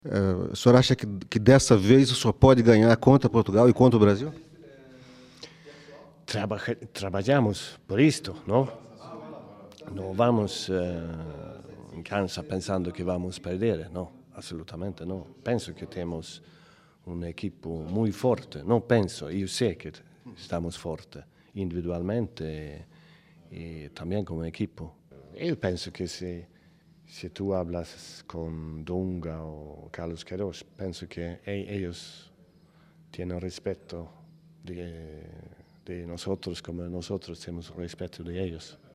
Entrevista com o técnico da Costa do Marfim, Sven-Göran Eriksson